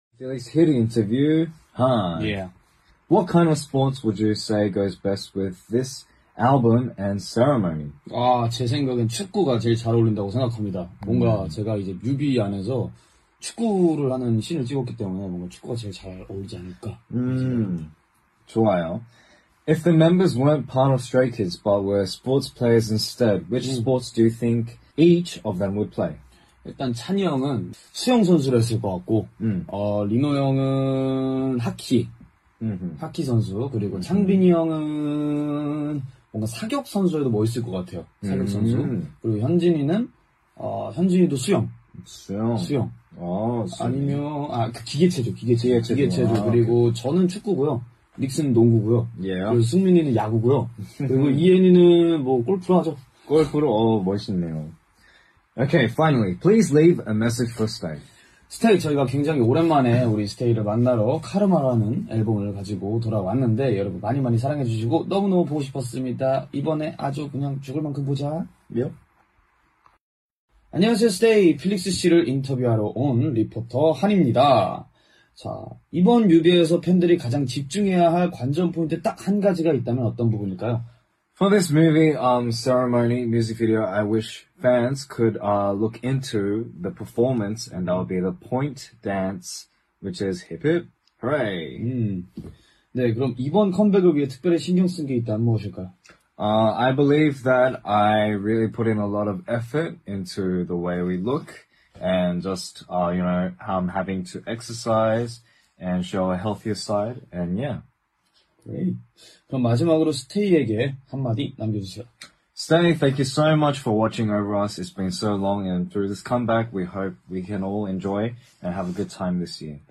Sunshine Twins Interview - KARMA